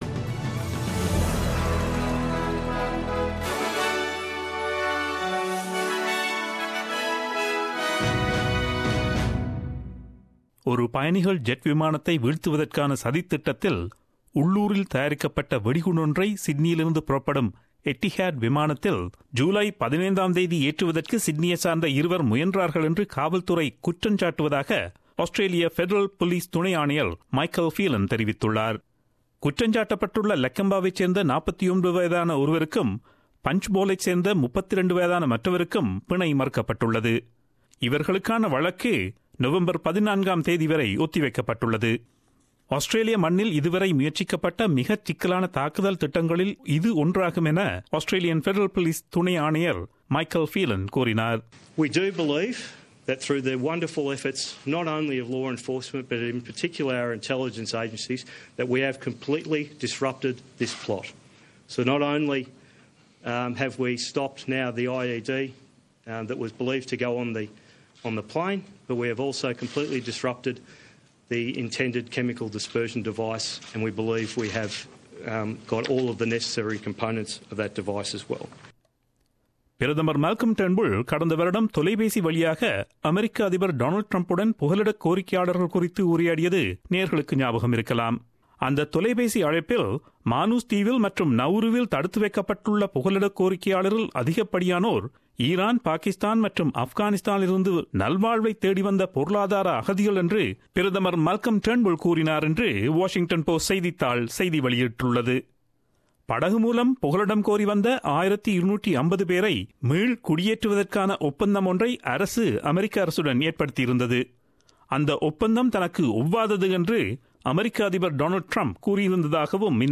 Australian news bulletin aired on Friday 04 August 2017 at 8pm.